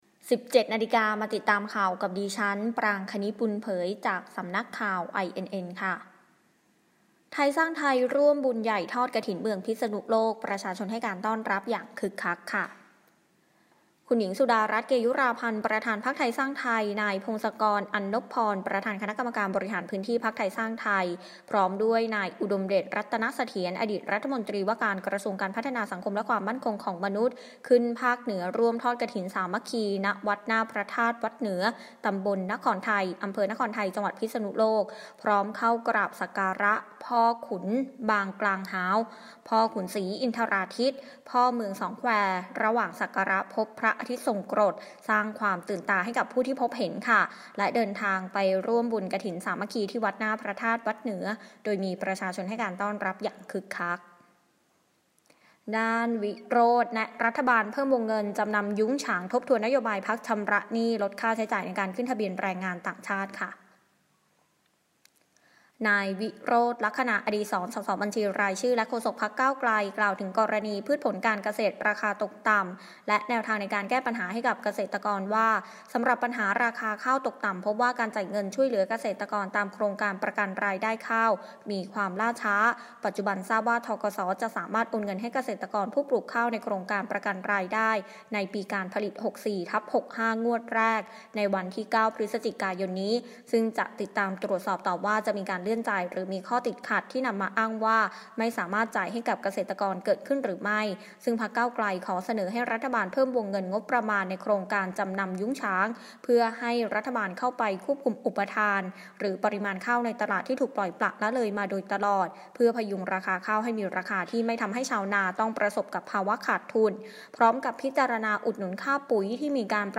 คลิปข่าวต้นชั่วโมง
ข่างต้นชั่วโมง 17.00 น.